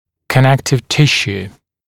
[kə’nektɪv ‘tɪʃuː] [-sjuː][кэ’нэктив ‘тишу:] [-сйу:]соединительная ткань